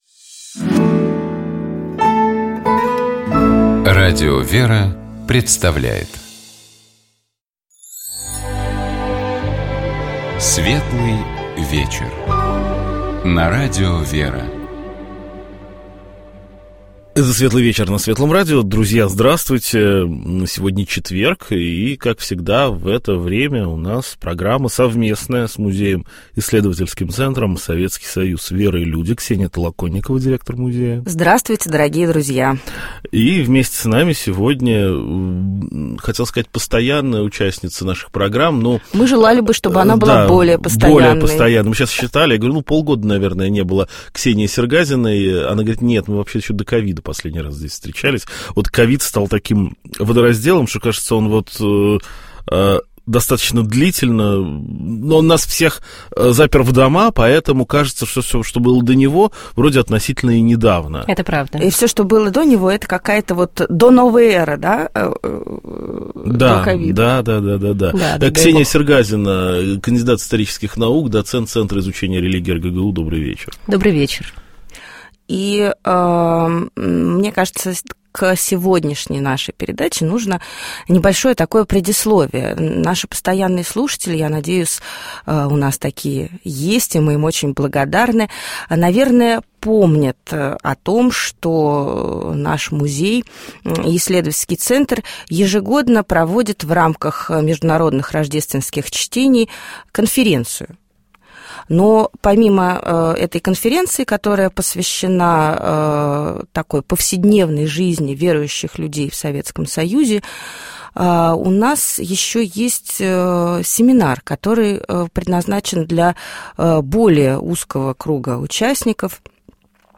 У нас в гостях была кандидат исторических наук